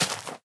grass.2.ogg